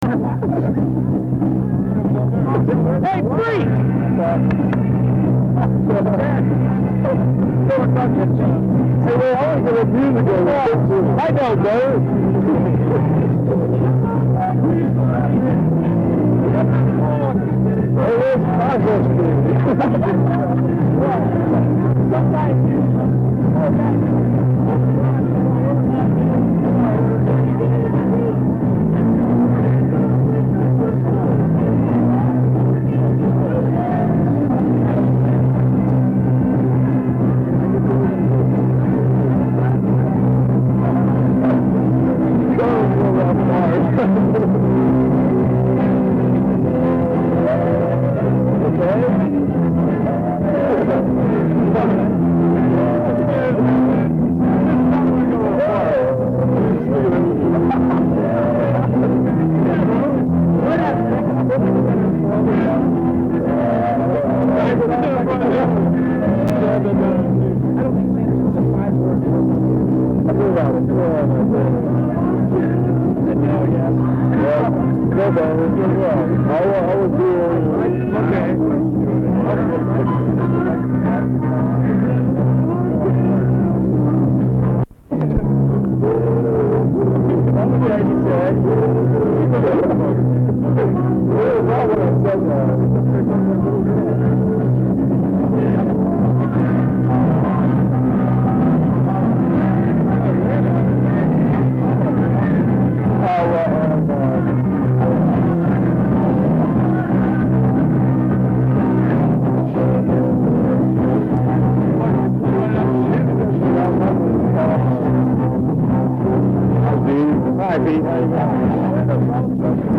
This was an outdoor concert held on a Sunday afternoon in the summer of 1972 in Harrison Smith Park in Upper Sandusky, Ohio.
Disclaimer: The audio in the clips is of extremely poor quality! Plus my friends and I were talking throughout.